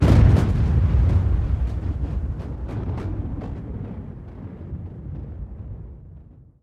SFX轻快的打雷声音效下载